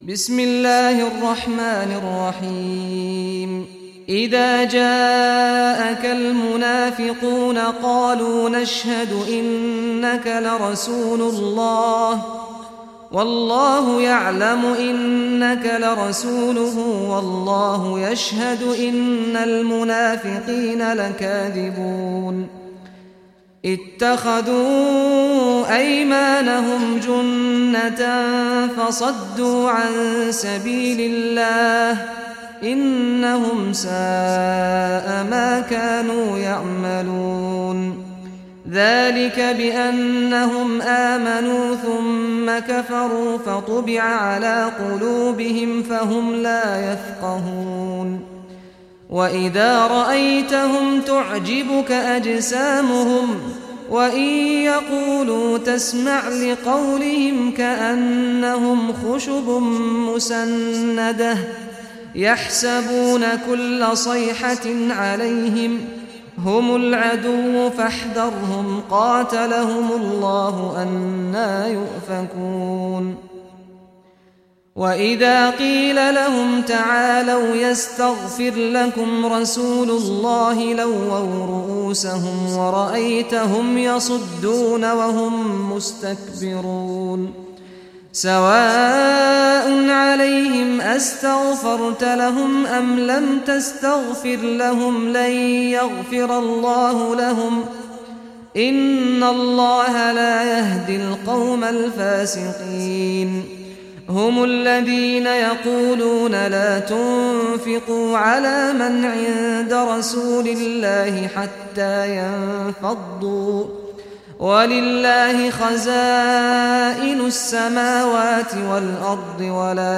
Surah Munafiqun Recitation by Sheikh Saad Ghamdi
Surah Al-Munafiqun, listen or play online mp3 tilawat / recitation in Arabic in the beautiful voice of Sheikh Saad al Ghamdi.